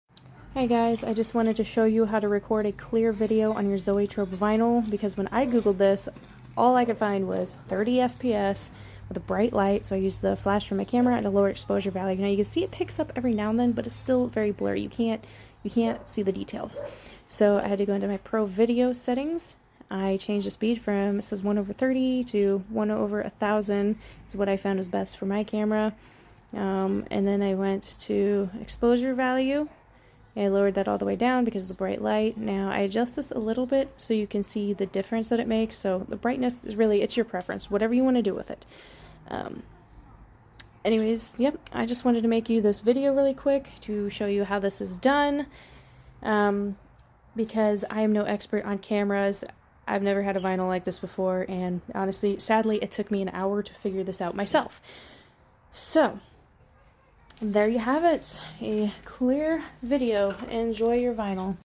This is how I recorded my vinyl on a Samsung Galaxy S23 Ultra.